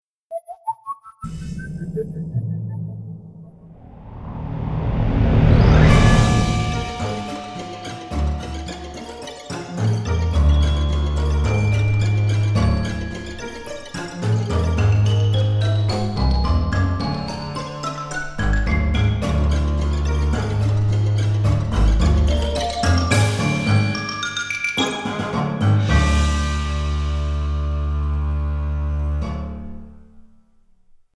Fun and quirky theme